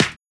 SNARE64.wav